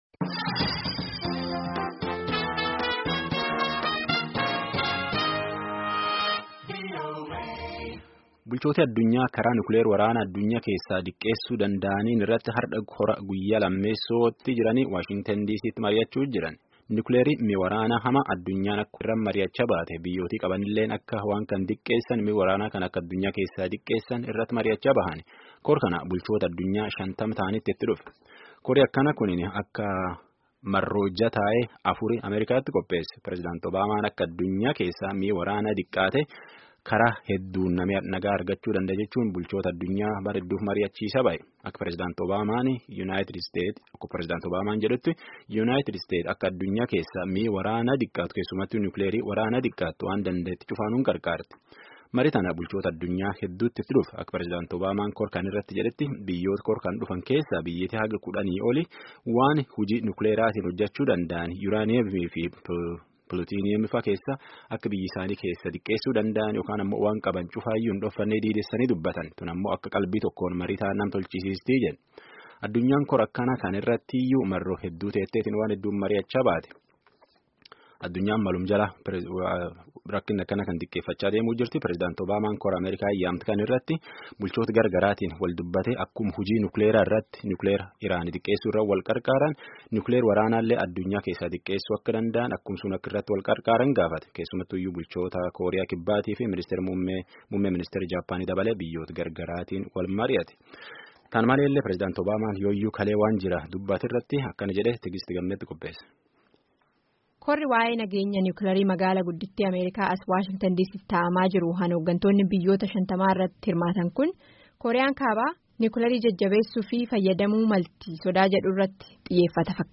Gabaasa sagalee.